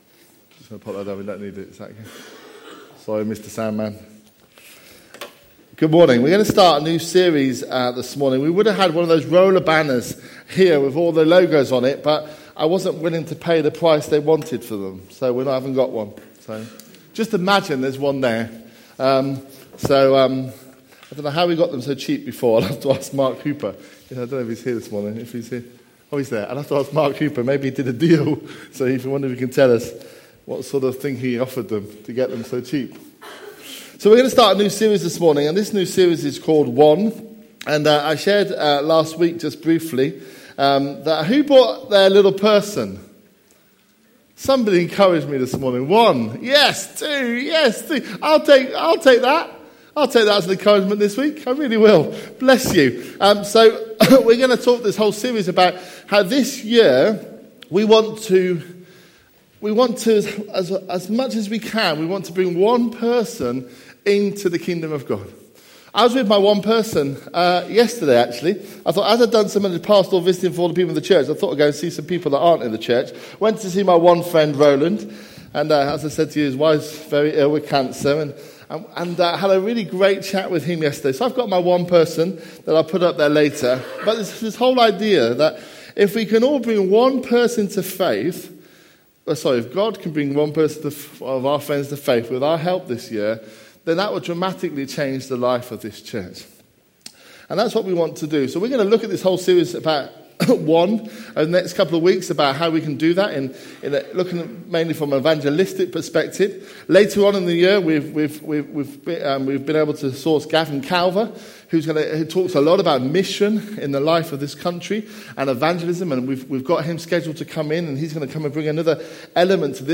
A message from the series "One."